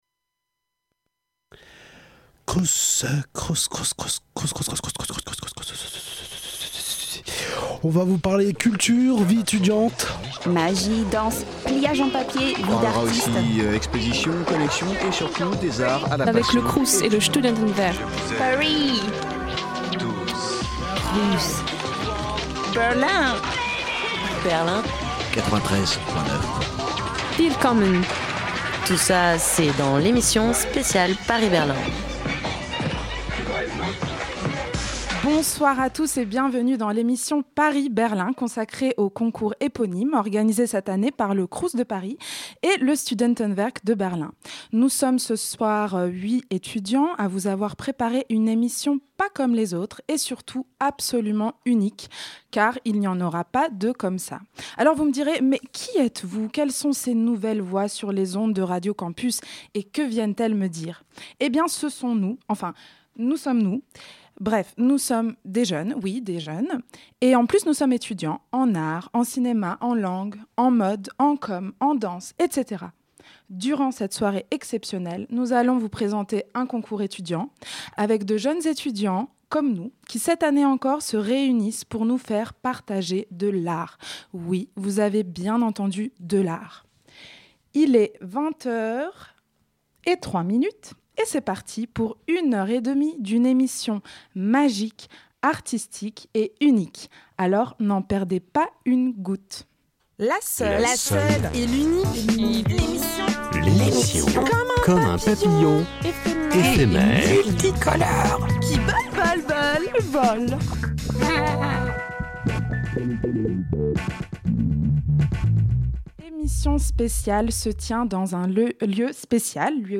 Cette émission a été enregistrée lors du vernissage de l’exposition PARIS-BERLIN au Centre Culturel du Crous, dans le 6e arrondissement de Paris.